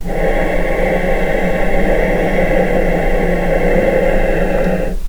vc-C#5-pp.AIF